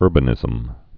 (ûrbə-nĭzəm)